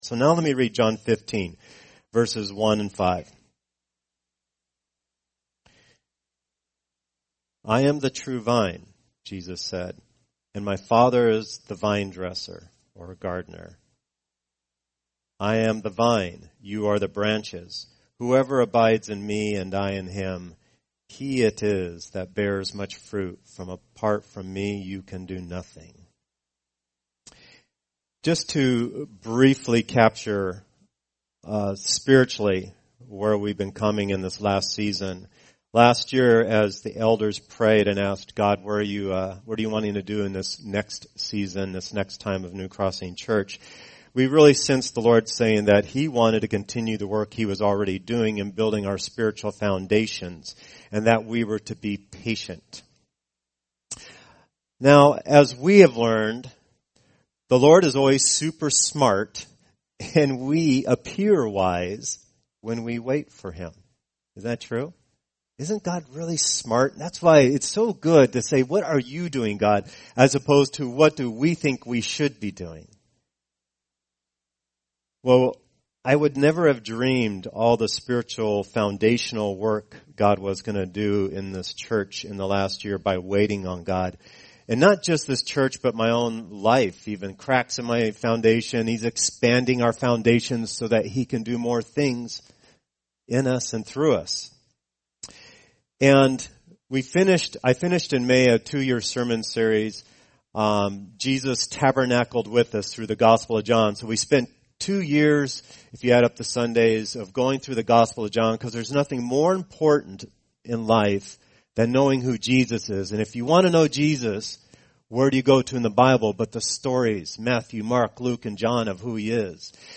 Annual Celebration Service 2012